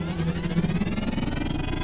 speed_up_4.ogg